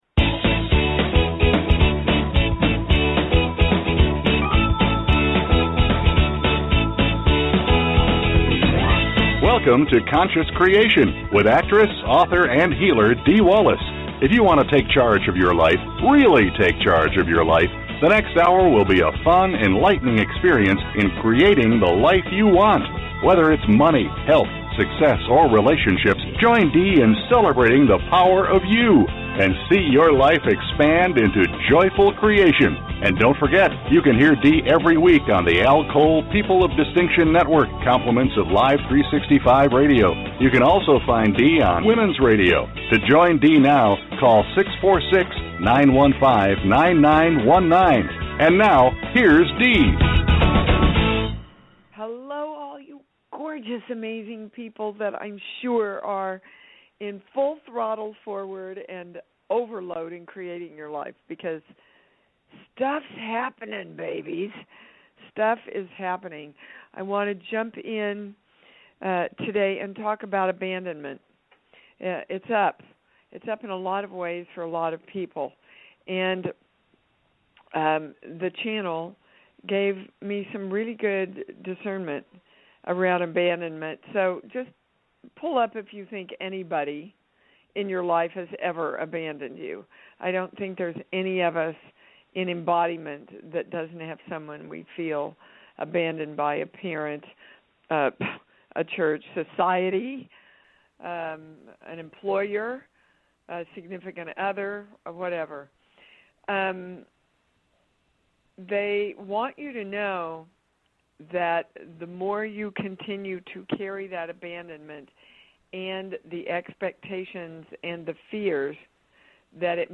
Subscribe Talk Show Conscious Creation Show Host Dee Wallace Dee's show deals with the latest energy shifts and how they correspond with your individual blocks.